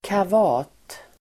Ladda ner uttalet
kavat adjektiv (om barn), plucky [used of children]Uttal: [kav'a:t] Böjningar: kavataSynonymer: oräddAnvändning: neutrum saknasDefinition: morsk, modig